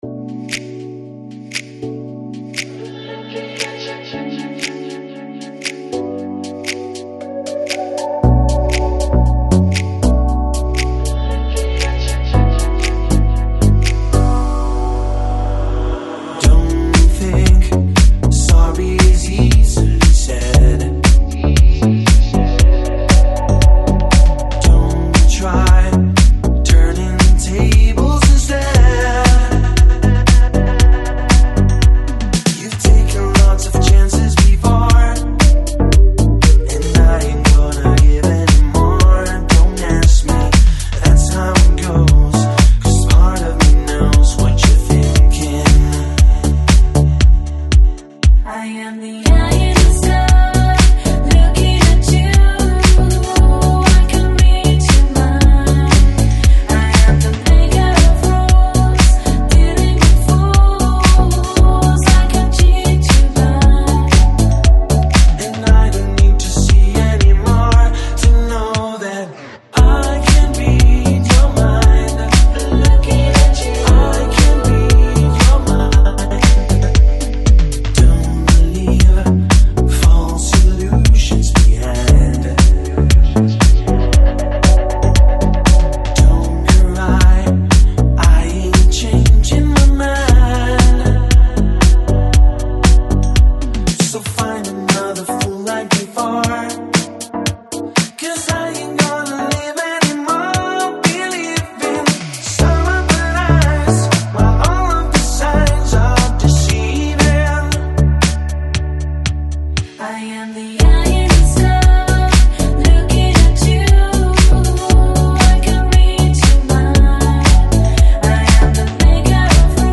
Electronic, Lounge, Chill Out, House, Pop Носитель